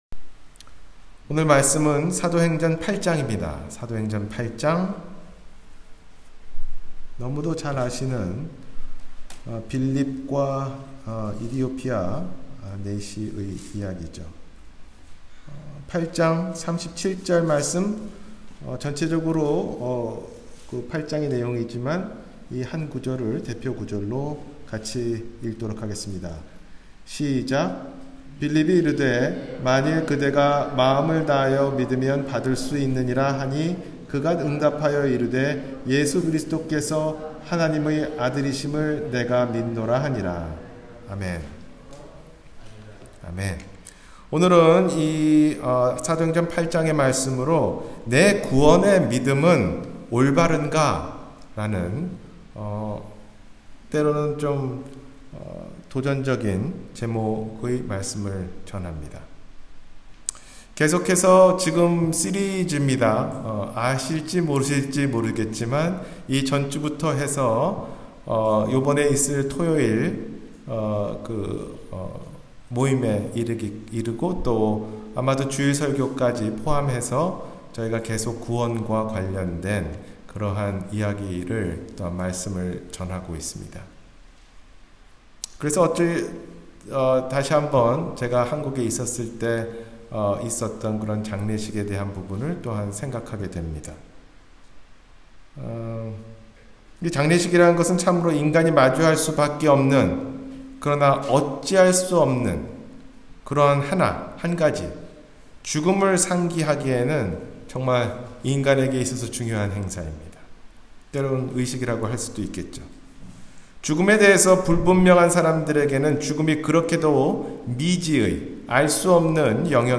내 구원의 믿음은 올바른가? – 주일설교